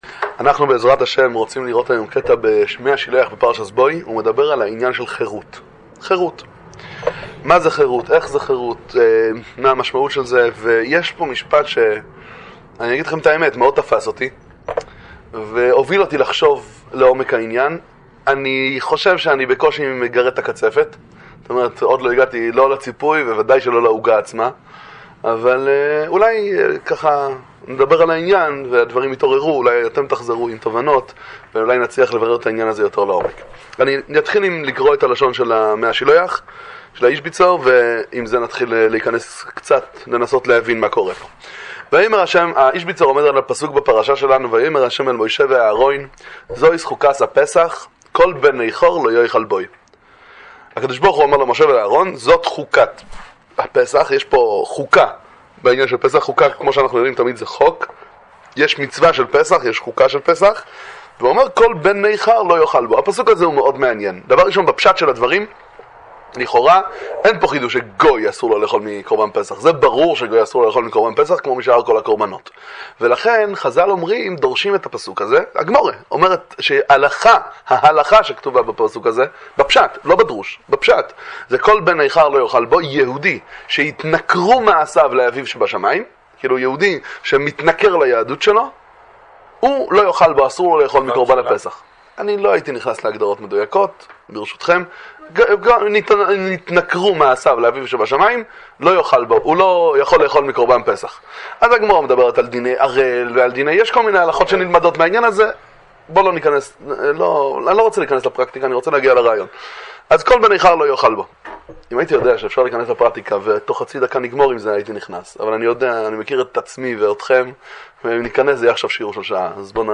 שיעור בספר מי השילוח איזביצא